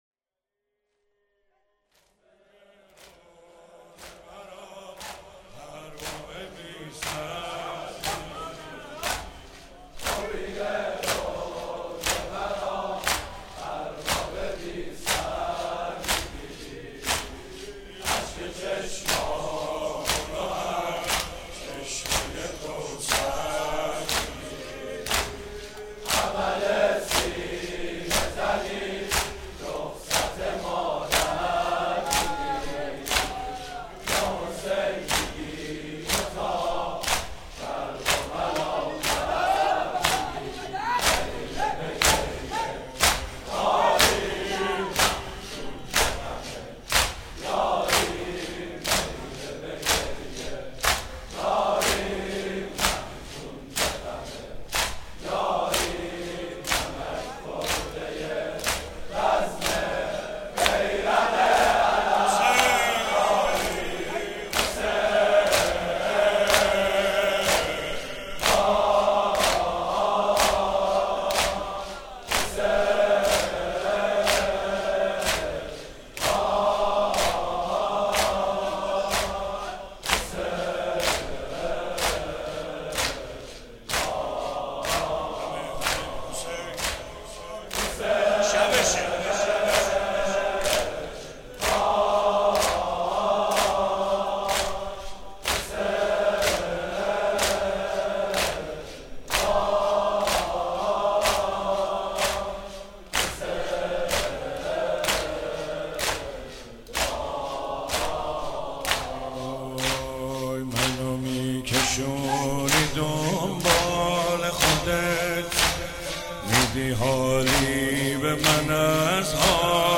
هفتگی 28 دی 96 - زمینه - منو میکشونی دنبال خودت